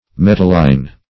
Metalline \Met"al*line\, a. [Cf. F. m['e]tallin.] (Chem.)